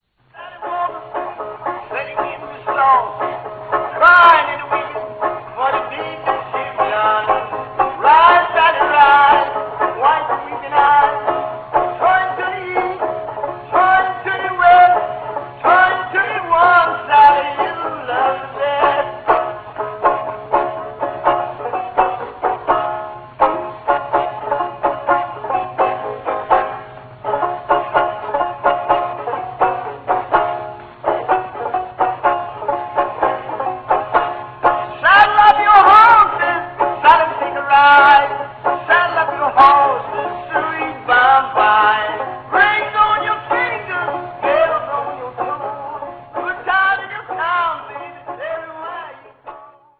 banjo style